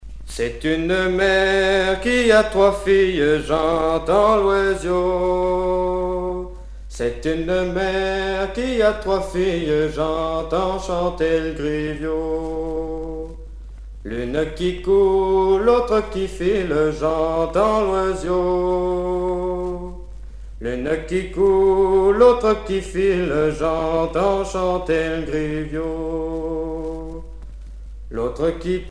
Région ou province Bourbonnais
Genre laisse
Pièce musicale éditée